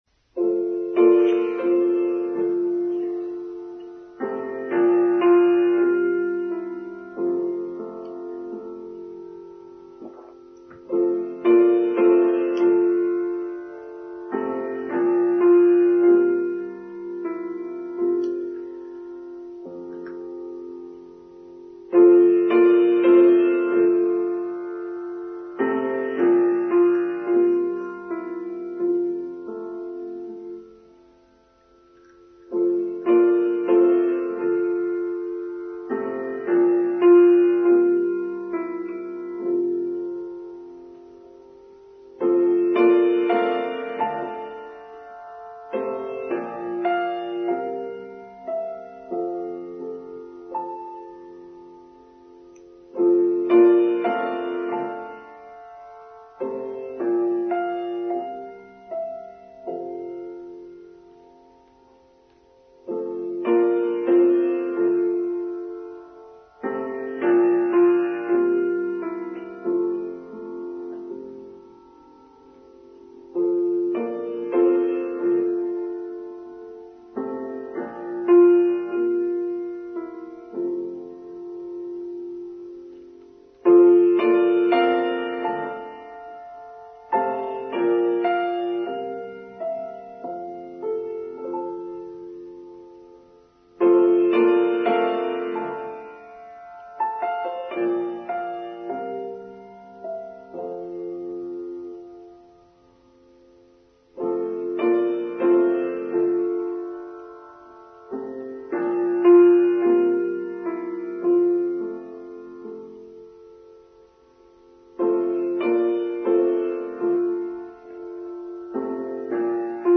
Benevolence: Online Service for Sunday 29th January 2023